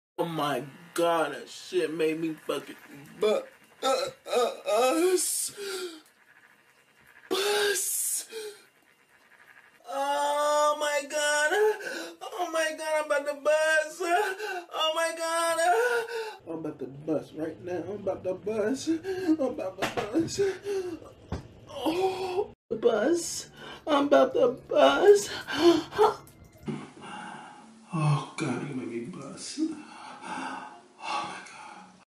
Bus